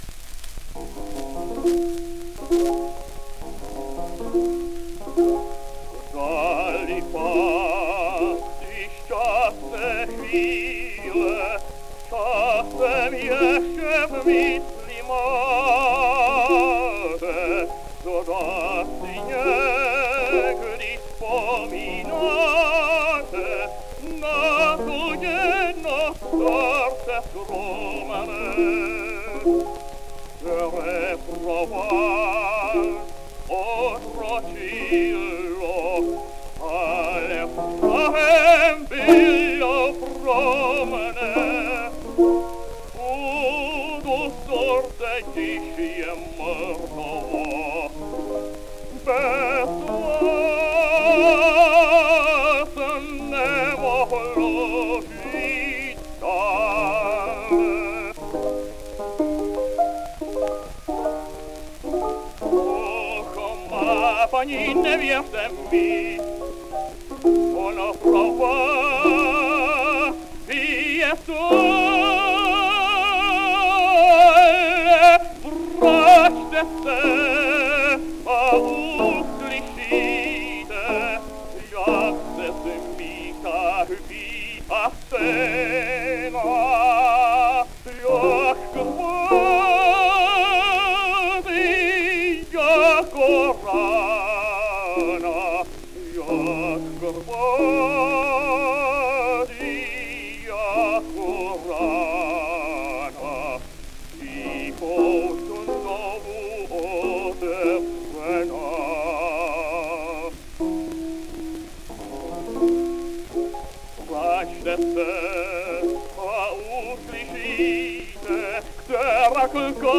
song cycle